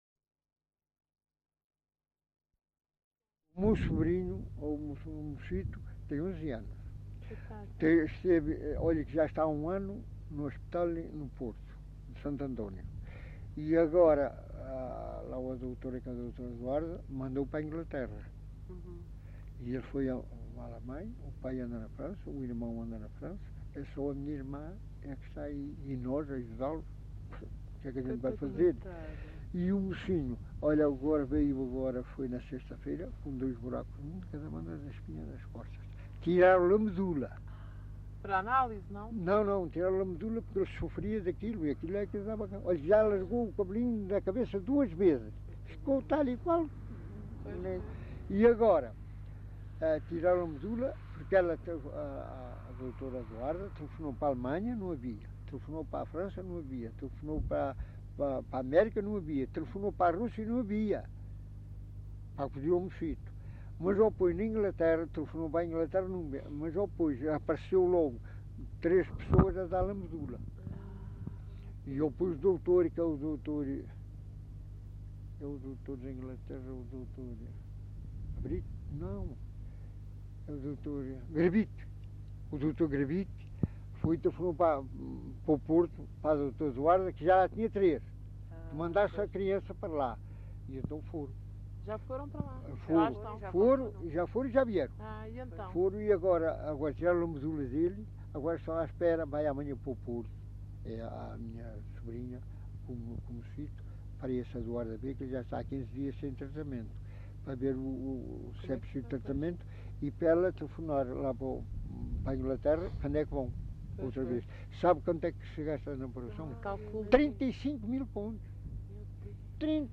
LocalidadeCovo (Vale de Cambra, Aveiro)